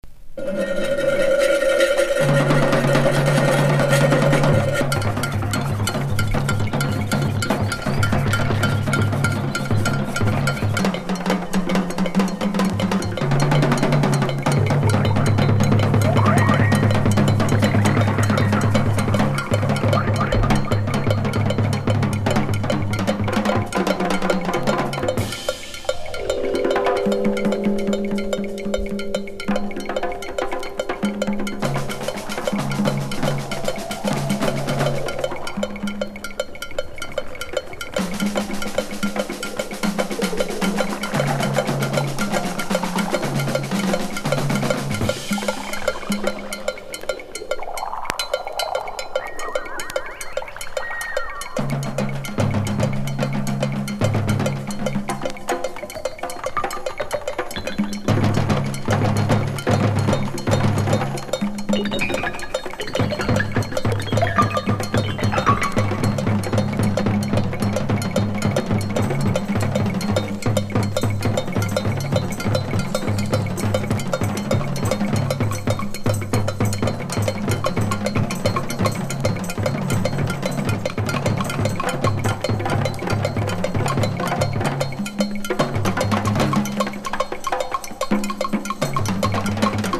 ブレイク# FREE / SPIRITUAL